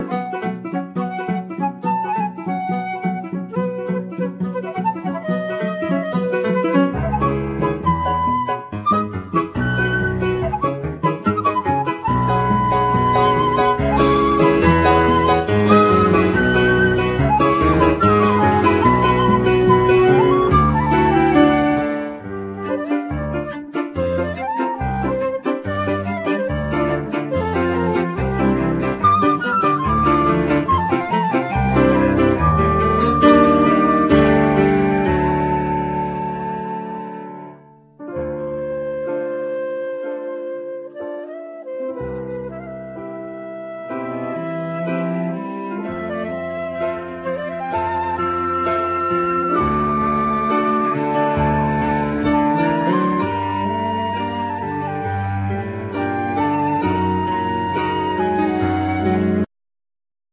Piano,Keyboards,Guitar,Trumpet,Vocal,etc
Cello,Saxophone,Vocal,etc
Flute,Piano,Keyboards,etc